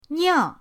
niao4.mp3